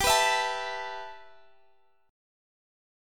G6add9 Chord (page 4)
Listen to G6add9 strummed